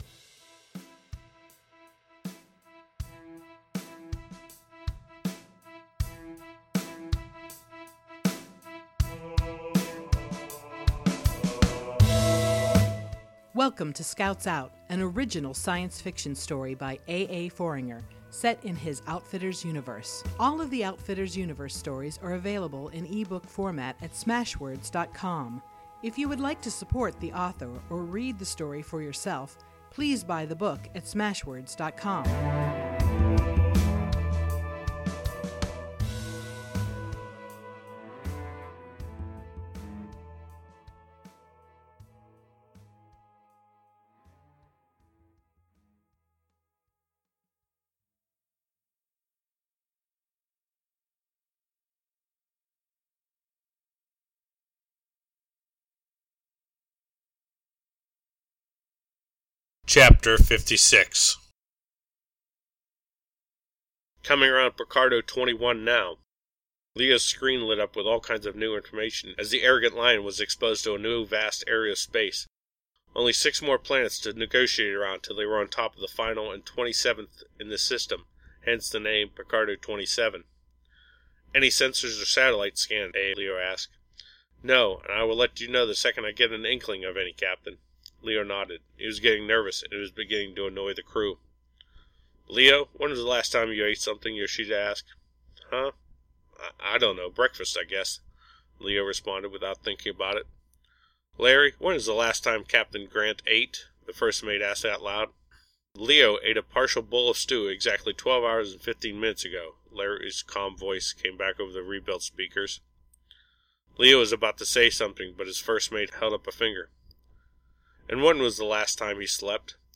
science fiction podcast